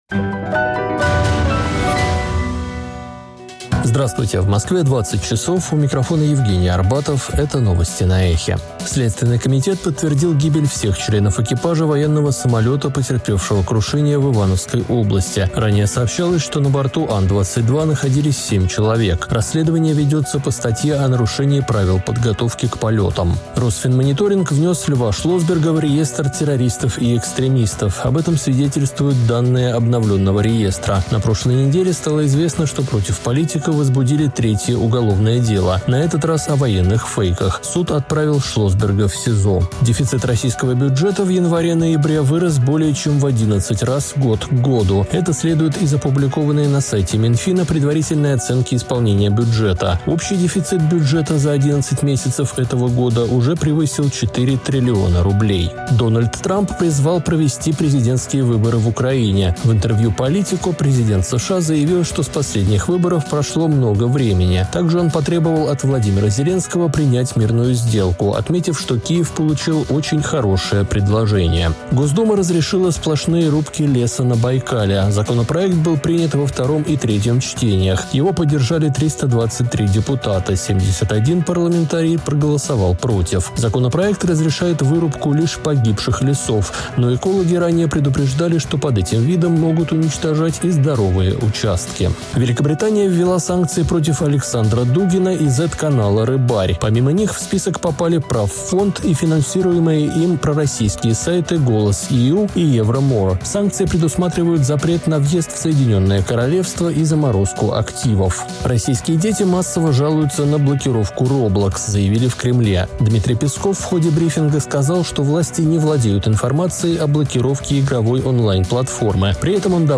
Слушайте свежий выпуск новостей «Эха»
Новости